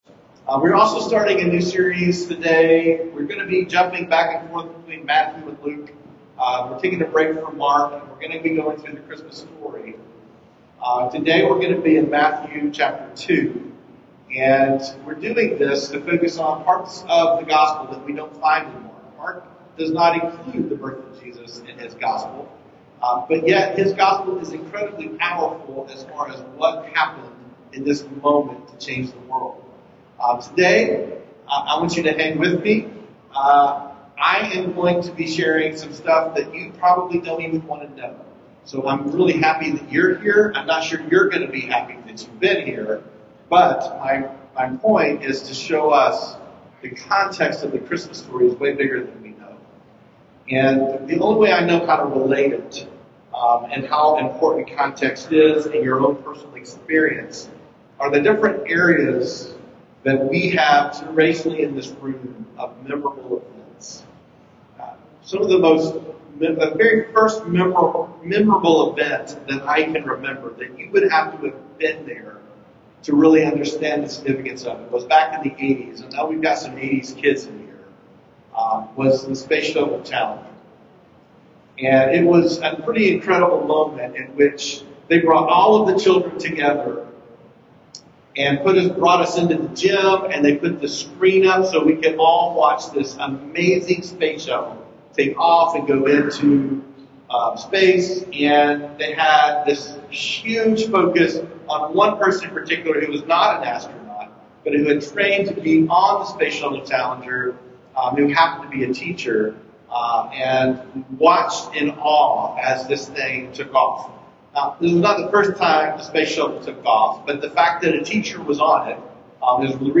Latest Sermon - Journey Church